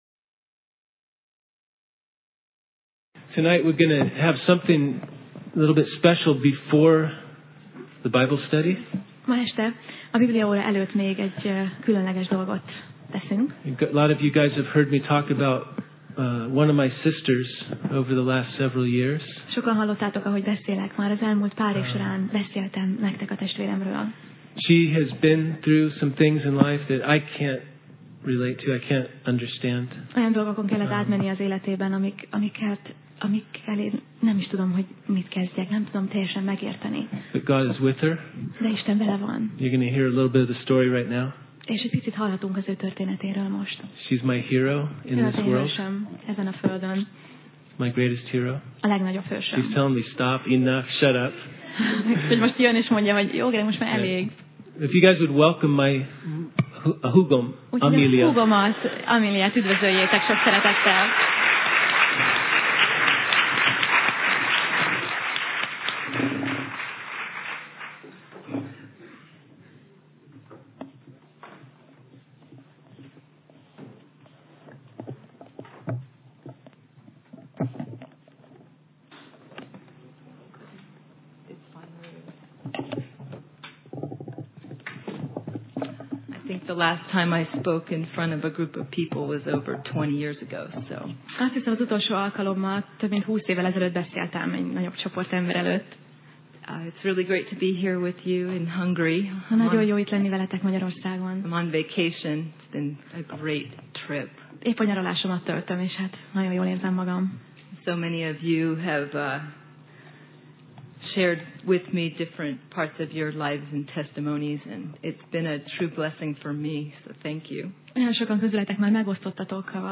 Tematikus tanítás Alkalom: Szerda Este